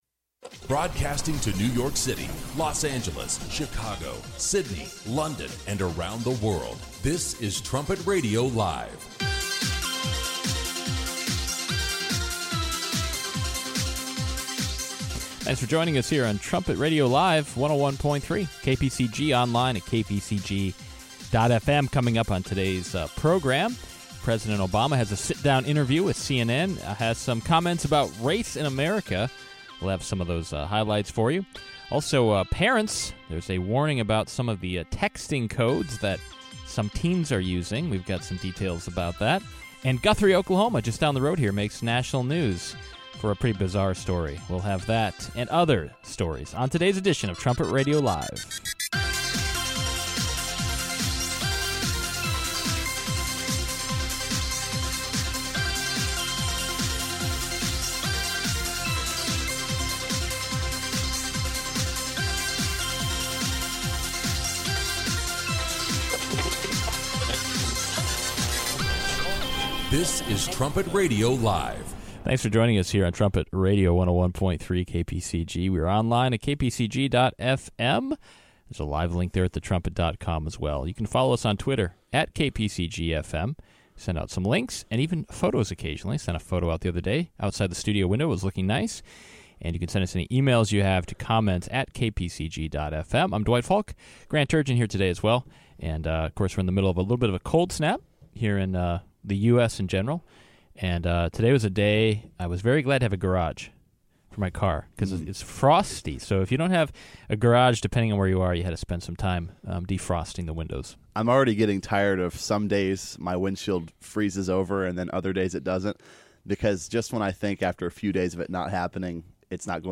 Trumpet Radio Live seeks to provide God’s view of the current headlines in an informative, stimulating, conversational and occasionally humorous way. Tune in each day at 10 a.m. CST to listen in live.